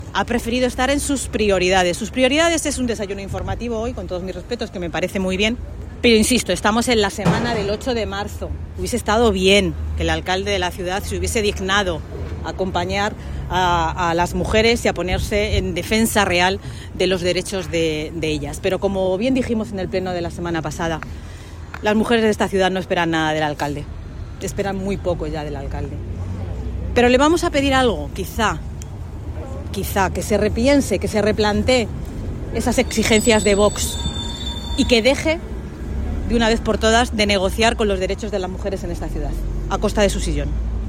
Noelia de la Cruz ha realizado estas declaraciones tras participar en la concentración mensual contra la violencia machista convocada por el Consejo Local de la Mujer de Toledo, a la que también han asistido concejales y concejalas del Grupo Municipal Socialista.